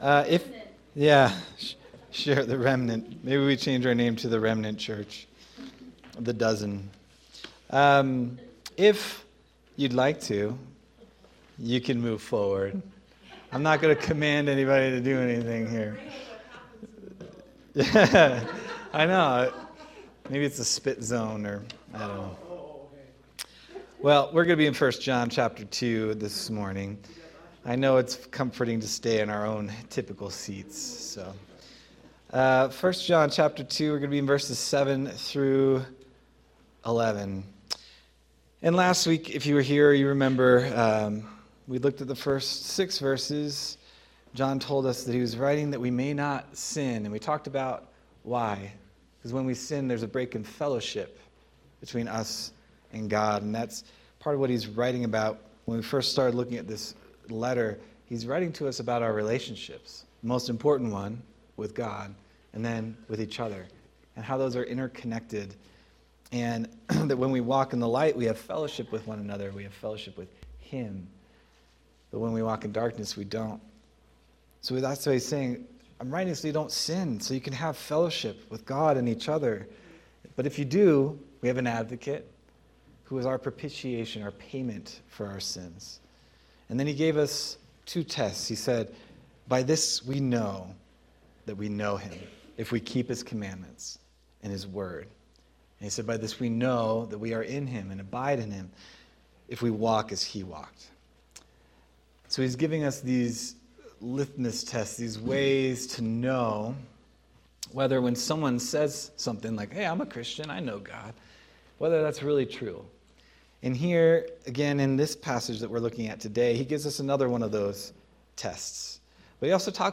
February 1st, 2026 Sermon